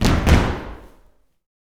YOUTHFEET4-L.wav